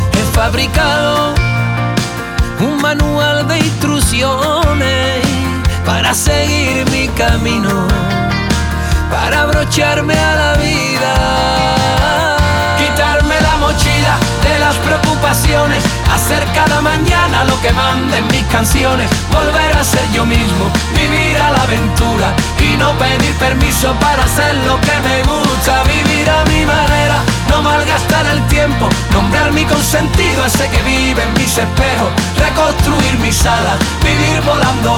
Pop Latino